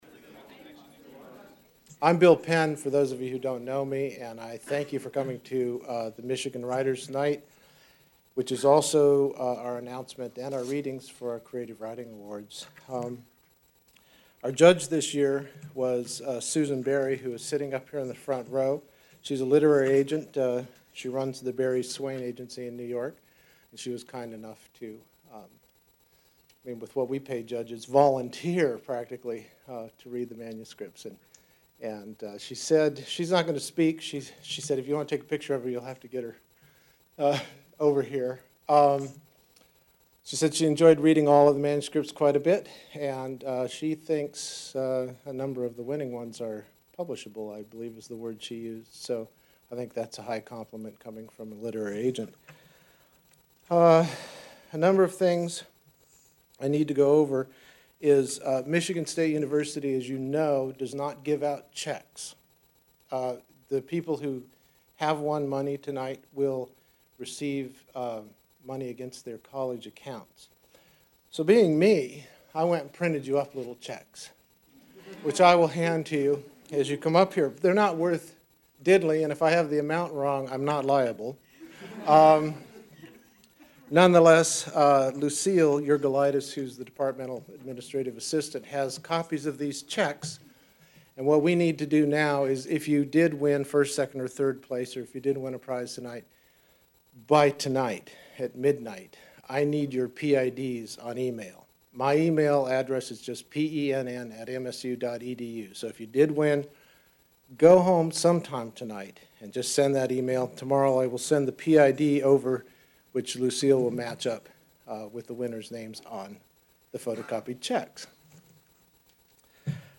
At the 2005 Michigan State University Student Writers Awards Night, students read from their original, award wining works.
Part of the MSU Libraries' Michigan Writers Series. Held in the MSU Main Library.